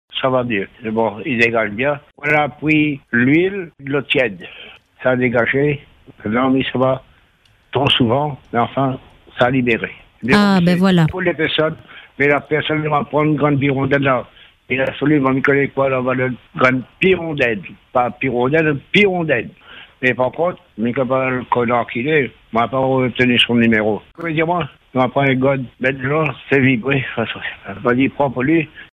Il nous a rappelés ce matin pour donner des nouvelles, et elles sont excellentes : tout est rentré dans l’ordre.